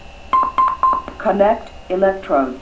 • AED Sounds
electrode.wav